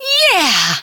Pauline saying "Yeah"
SMO_Pauline_Voice_Yeah.oga.mp3